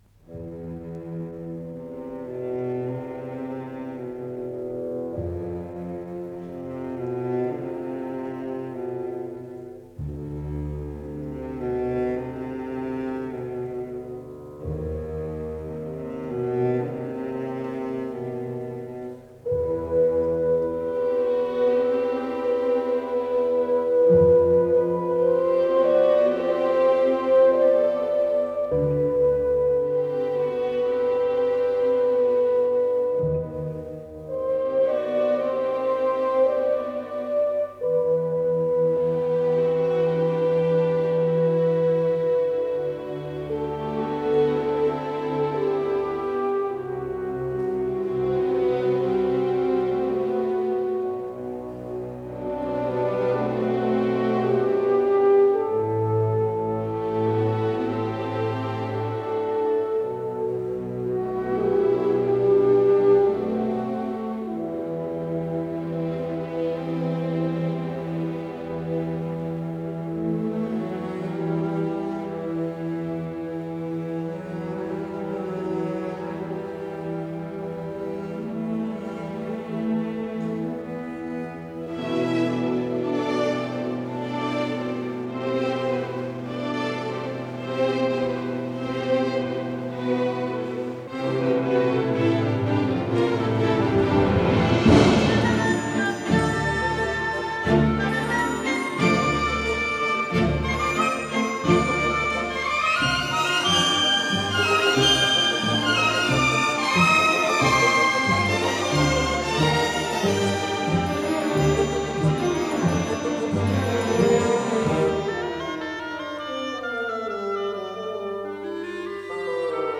Симфоническая поэма